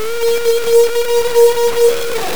INTERSEA FOUNDATION Humpback Whale Sound Archives
The examples below are short samples of recordings of vocalizations that were captured in the humpback whale feeding waters of Southeast Alaska.
These vocalizations were recorded on an Intersea Foundation expedition.